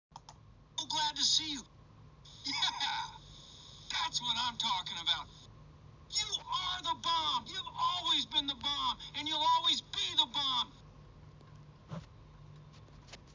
Grosser singender Folienballon
• 🎶 Mit Musikfunktion – sorgt für Partystimmung